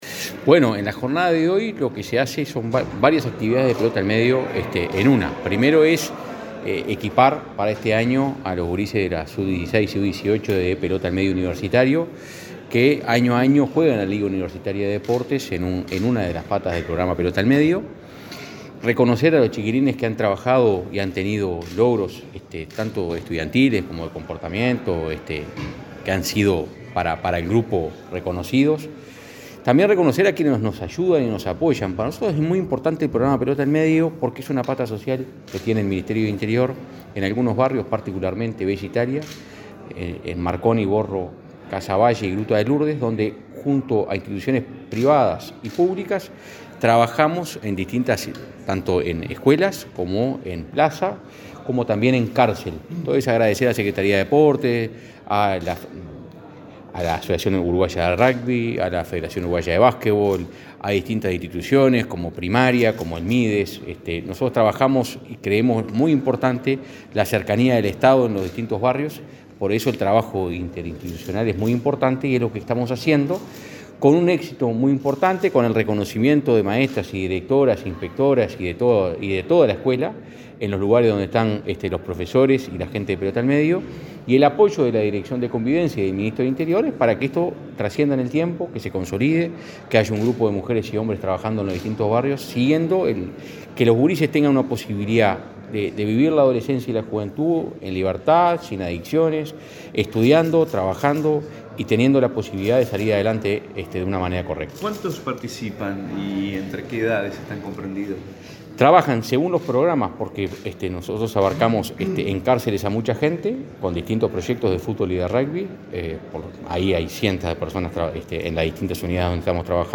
Declaraciones a la prensa del director de Convivencia Ciudadana, Santiago González